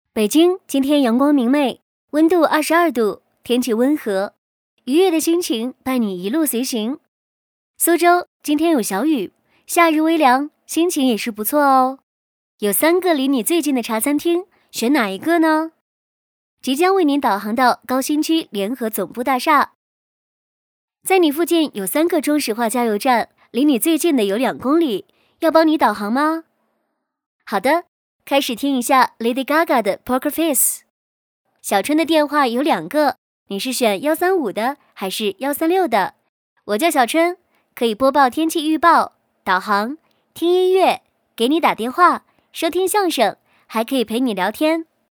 女36-【人工智能】AI小春
女36年轻多风格 v36
女36--人工智能-AI小春.mp3